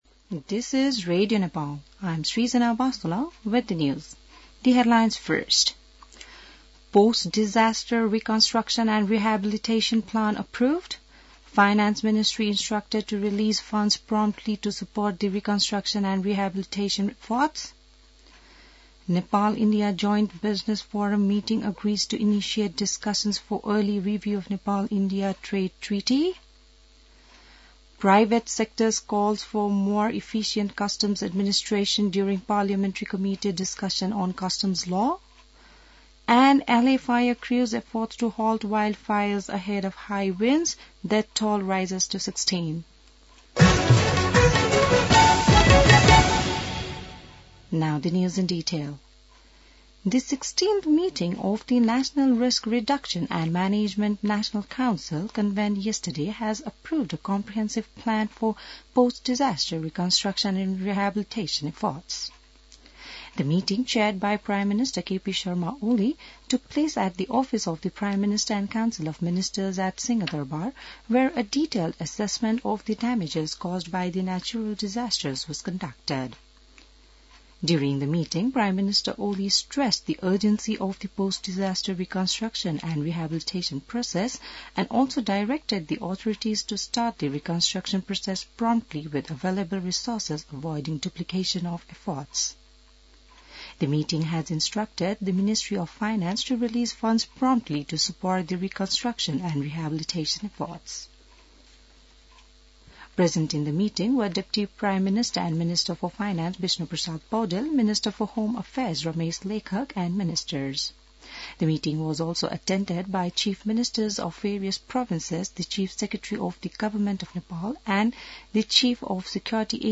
बिहान ८ बजेको अङ्ग्रेजी समाचार : १ माघ , २०८१